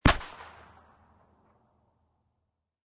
Descarga de Sonidos mp3 Gratis: explosion petardo 1.